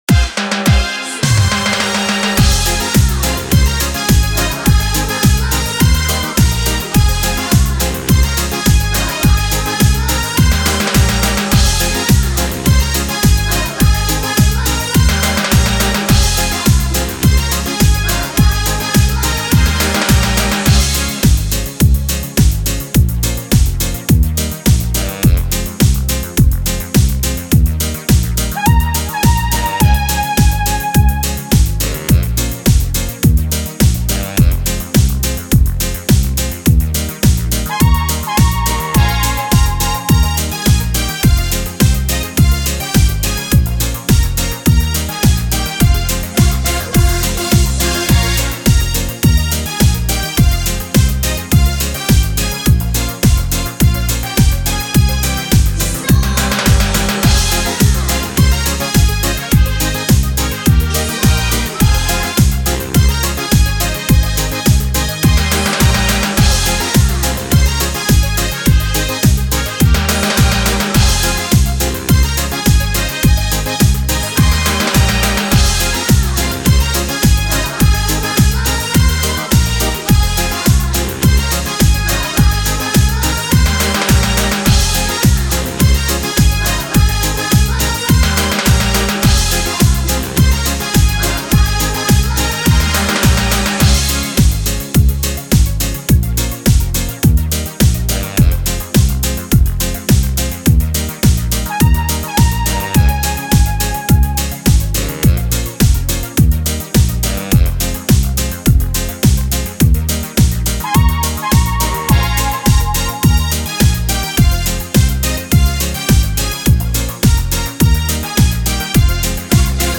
современные, весёлые, новые песни
детская песня